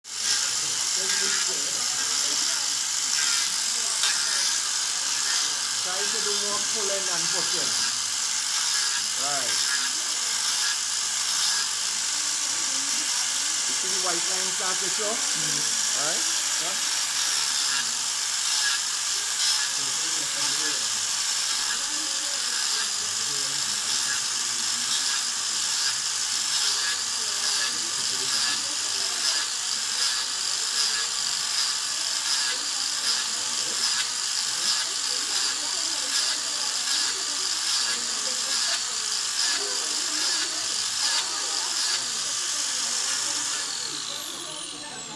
1. Using Hoof Grinder.mp4